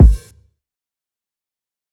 TC Kick 11.wav